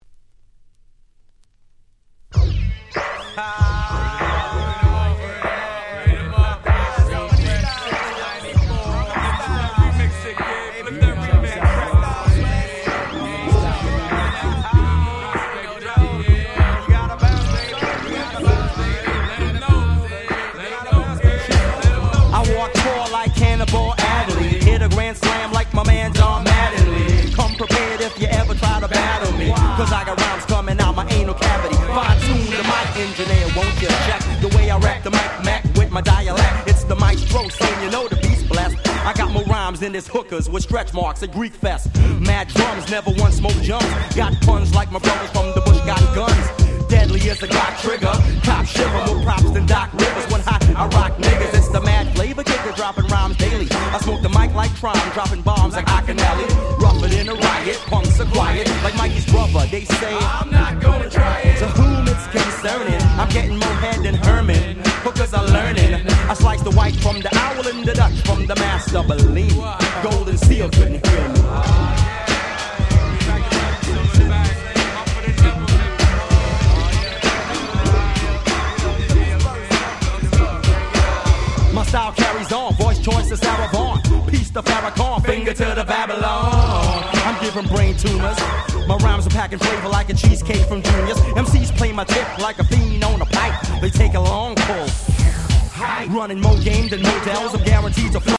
Canadian Hip Hop最高峰！！
コチラも上記の2曲と甲乙付け難いDopeなBoom Bapチューンで言う事無し！！